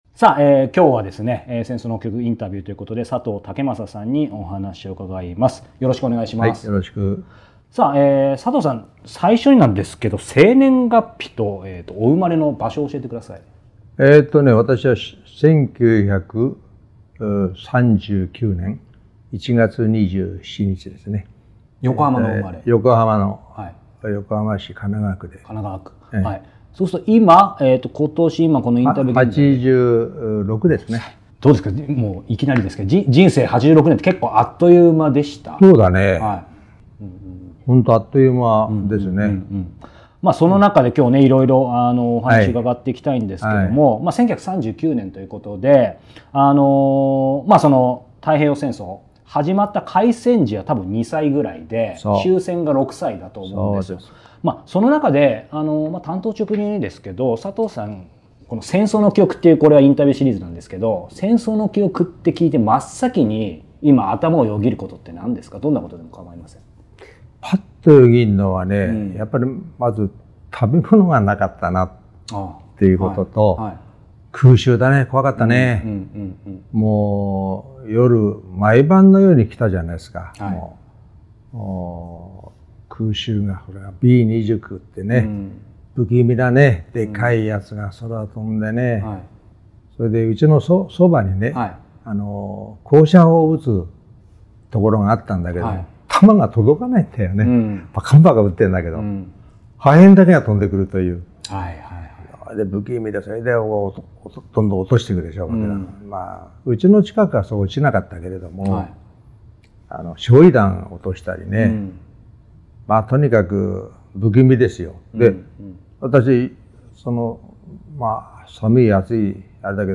戦争の記憶は、戦争体験者の肉声を残すプロジェクト。終戦から70年経ち、戦争体験を語れる方は年々少なくなっています。